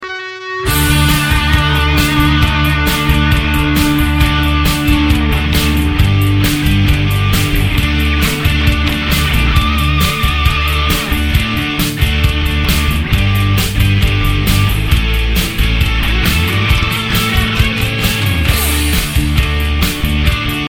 MP3 Demo Instrumental Version